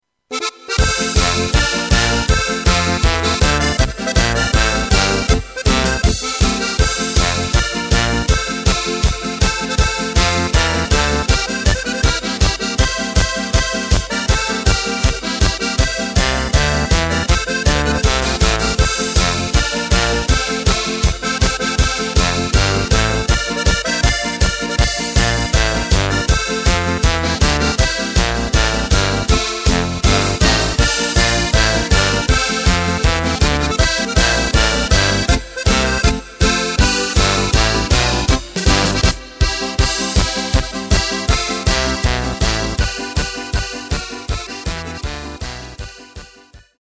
Tempo:         160.00
Tonart:            F
Flotte Polka aus dem Jahr 2020!
Playback mp3 mit Drums und Lyrics